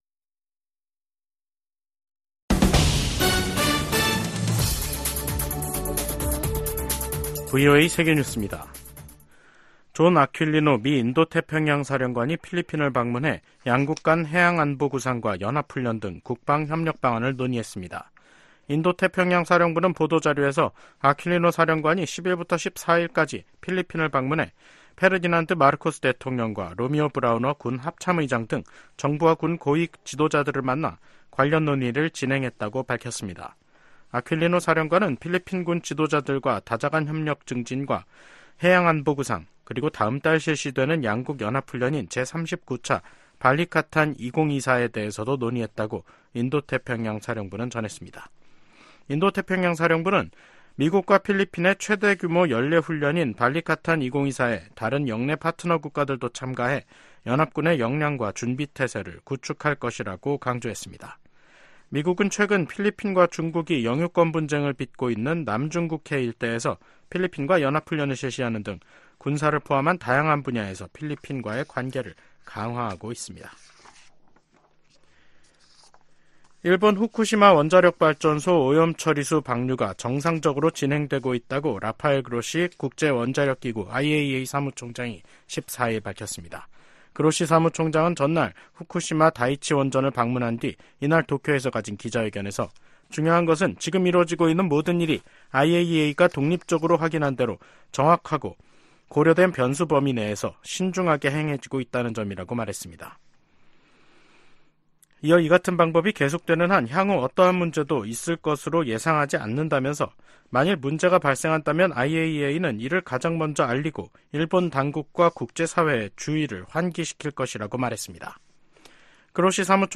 VOA 한국어 간판 뉴스 프로그램 '뉴스 투데이', 2024년 3월 14일 3부 방송입니다. 북한이 자체 핵우산을 가지고 있다는 블라디미르 푸틴 러시아 대통령의 발언에 관해, 미 국무부가 북-러 협력 강화에 우려를 나타냈습니다. 김정은 북한 국무위원장이 신형 탱크를 동원한 훈련을 현지 지도하면서 또 다시 전쟁 준비 완성을 강조했습니다. 미 의회 내 중국위원회가 북한 노동자를 고용한 중국 기업 제품 수입 즉각 중단을 행정부에 촉구했습니다.